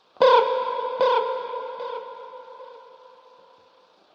恐怖的声音 " 深度挖掘或滴水不漏01
描述：快速的滴水声，或者黑暗的地窖里闷闷的脚步声，
Tag: 脚步 爬行 爬行 哭了 可怕 闹鬼 运行 吉他 恐怖 SFX 怪物 怪物 恐怖 木材 延迟 咆哮 环境 回声 昆虫 点击